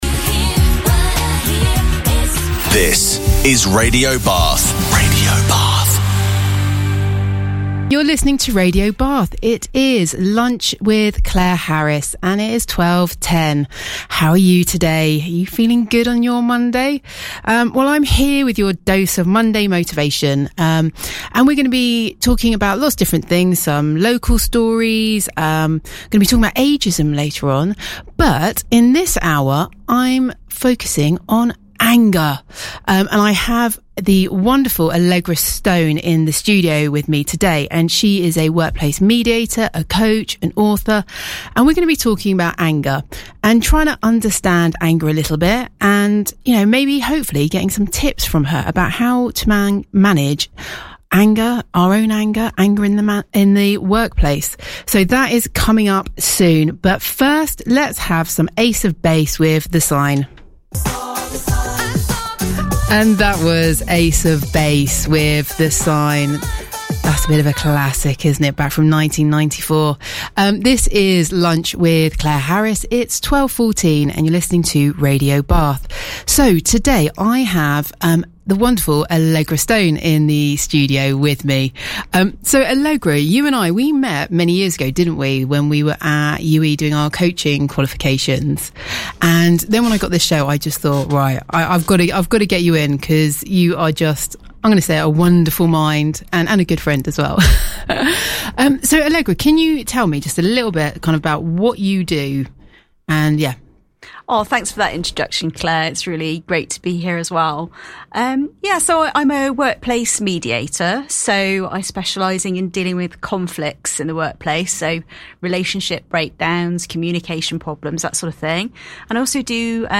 Bath Radio Interview